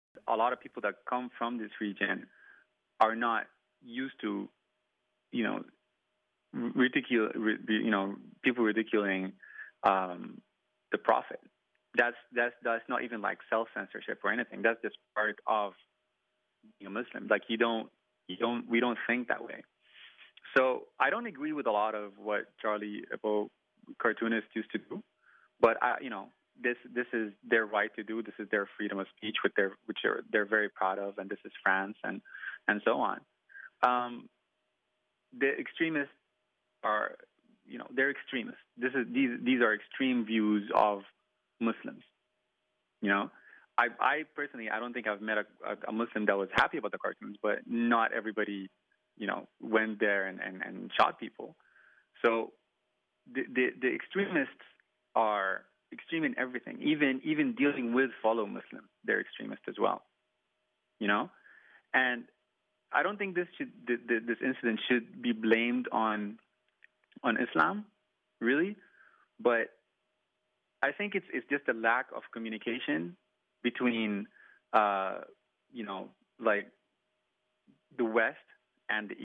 Sudanese cartoonist